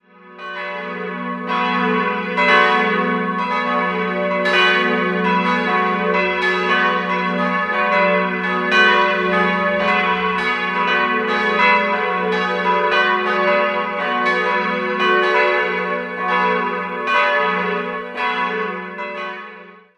Dominikus Barbieri erweiterte 1746 den Bau und stattete ihn im Barockstil aus. 3-stimmiges Gloria-Geläute: fis'-gis'-h' Die drei Glocken wurden im Jahr 1952 von Karl Czudnochowsky in Erding gegossen.